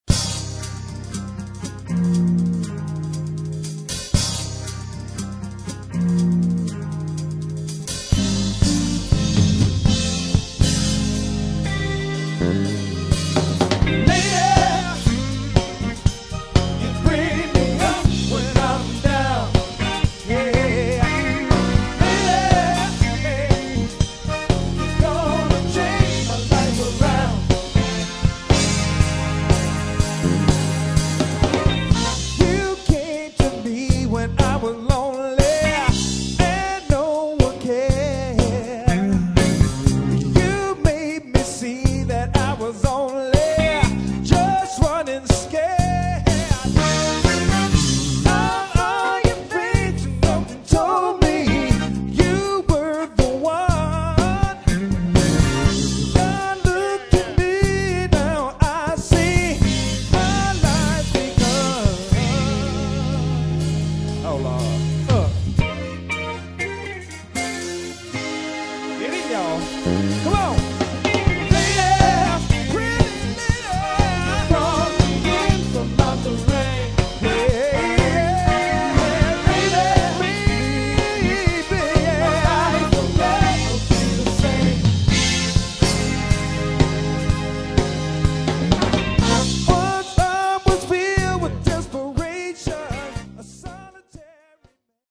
funk and R&B